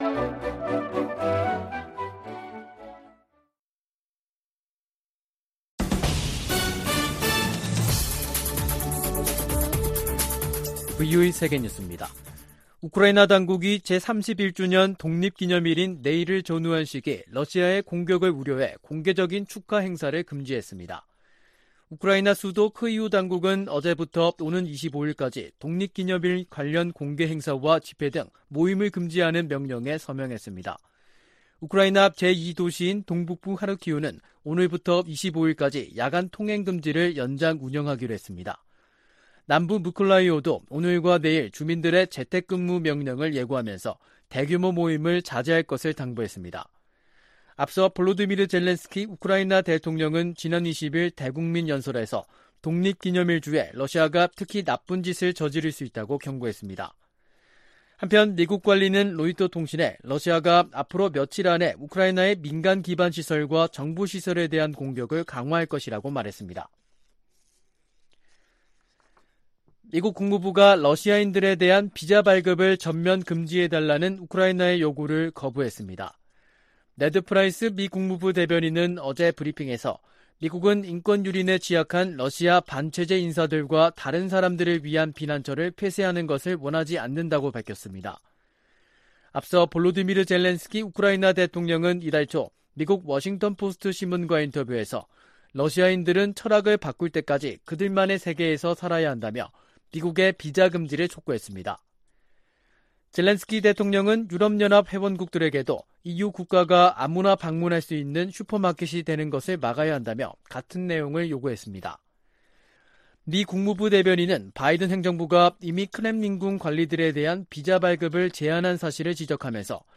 VOA 한국어 간판 뉴스 프로그램 '뉴스 투데이', 2022년 8월 23일 2부 방송입니다. 미 국무부는 한국 윤석열 정부의 ‘담대한 구상’이 미국 정부의 접근법과 일치한다며, 북한의 긍정적 반응을 촉구했습니다. 한국이 사상 처음으로 호주의 대규모 다국적 연합훈련인 피치블랙 훈련에 참가한다고 호주 국방부가 확인했습니다. 중국이 주한미군의 고고도 미사일 방어체계 즉 사드(THAAD)에 관해 한국에 압박을 이어가고 있습니다.